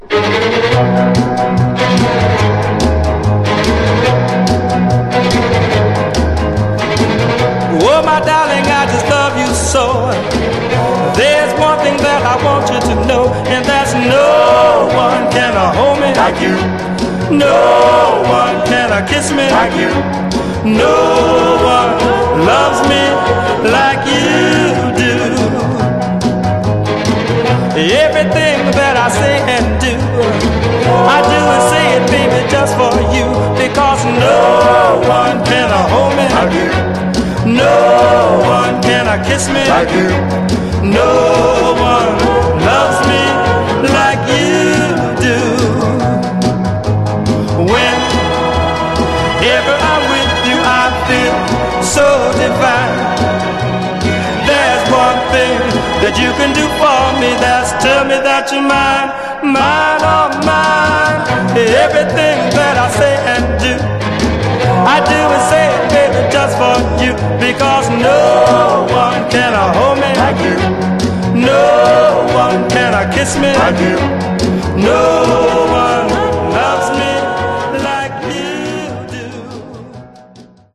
Genre: Vocal Groups (Doo-Wop)